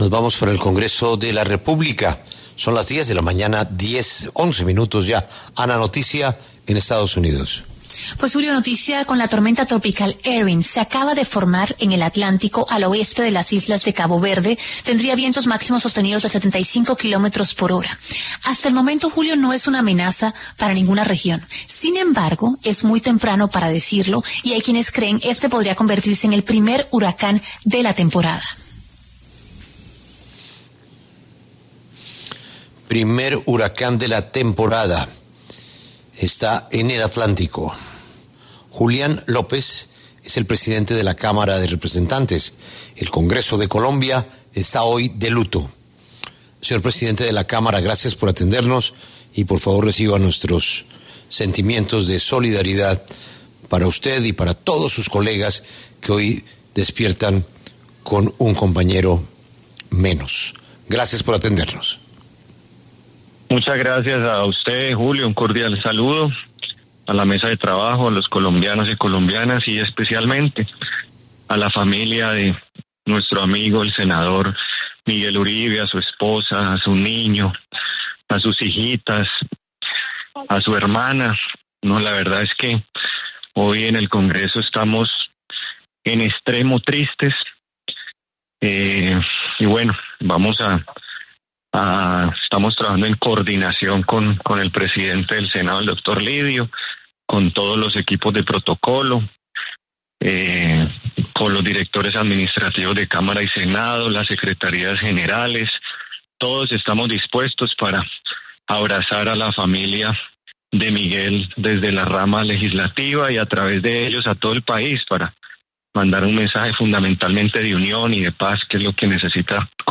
El presidente de la Cámara de Representantes, Julián López, habló en La W y dijo que en Colombia se necesitan “hombres y mujeres dispuestos a trabajar por la paz”.